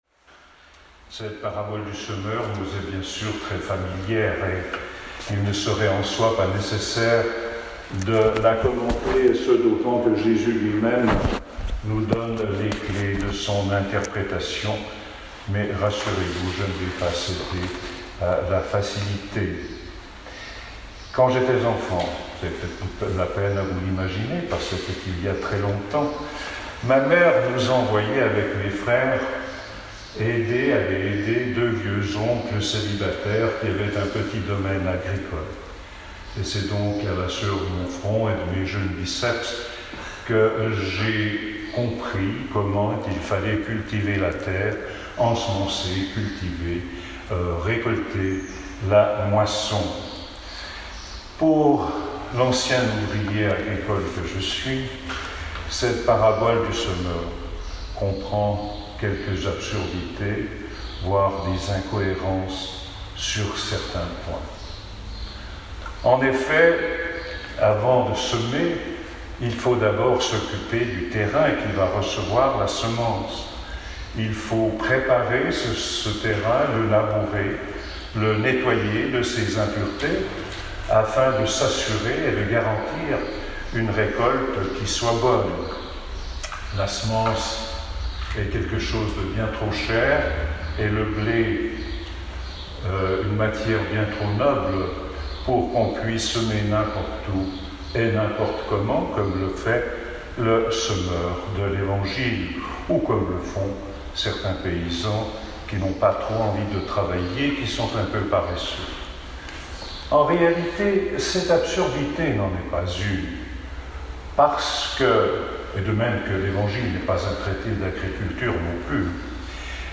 Comme il est d'usage pendant cette période, nous vous proposons un enregistrement en direct de l'homélie